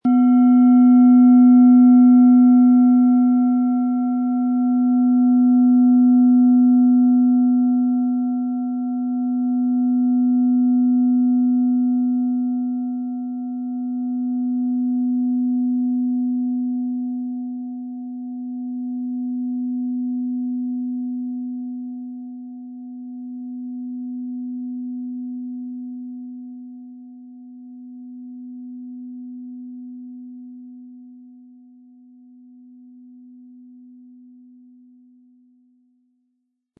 Um den Original-Klang genau dieser Schale zu hören, lassen Sie bitte den hinterlegten Sound abspielen.
SchalenformBihar
HerstellungIn Handarbeit getrieben
MaterialBronze